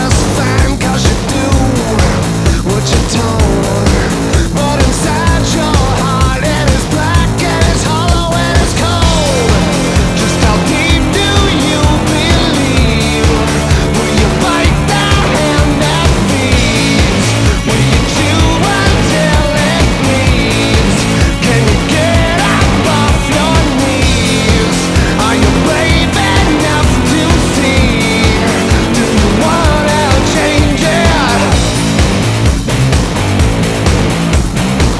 Жанр: Рок / Альтернатива / Электроника / Метал